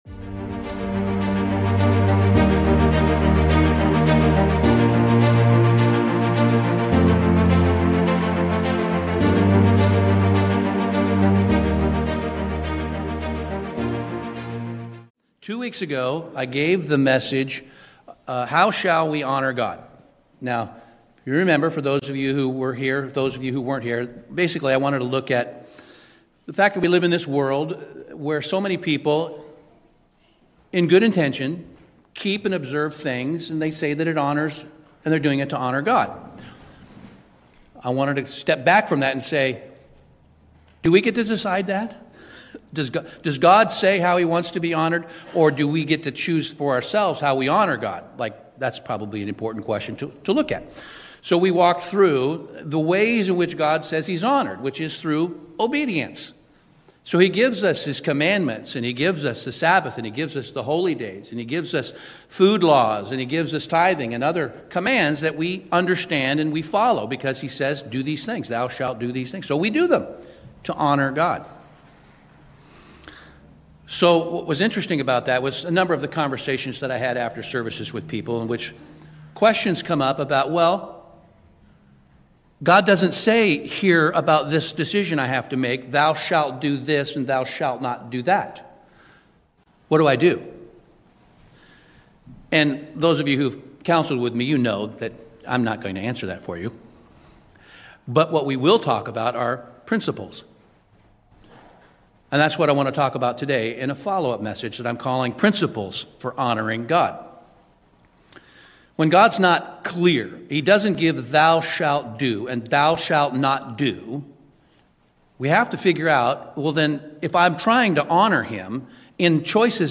This sermon shows how mature discernment works—how we apply biblical principles, train a trustworthy conscience, and evaluate choices through the lens of God’s character rather than cultural habit or personal preference.